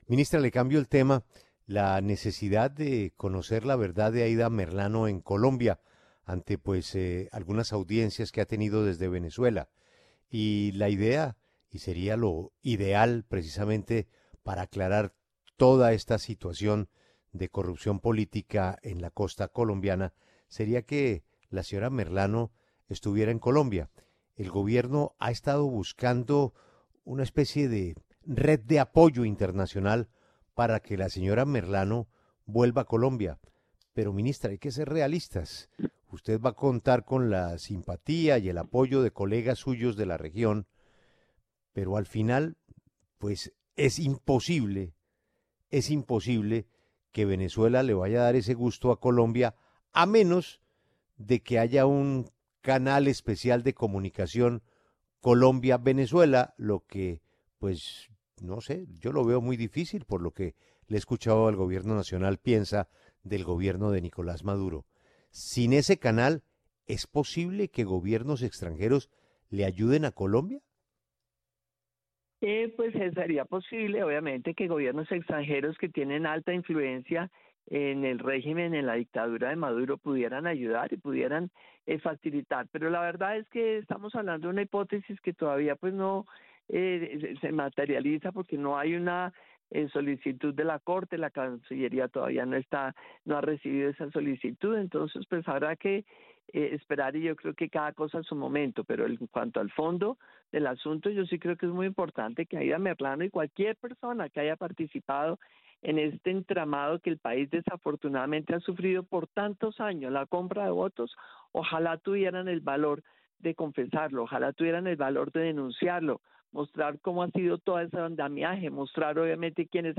Sin embargo, la alta funcionaria aseguró en los micrófonos de W Radio que aún no está en firme esta petición.